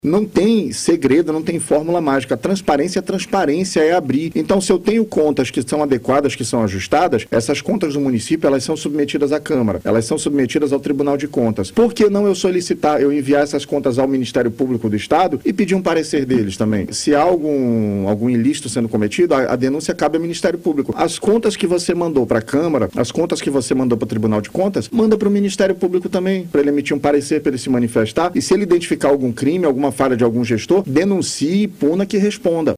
Entrevista
A entrevista exclusiva ocorreu nesta quinta-feira, 09, durante o BandNews Amazônia 1ª Edição.